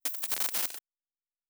pgs/Assets/Audio/Sci-Fi Sounds/Electric/Glitch 3_04.wav at master
Glitch 3_04.wav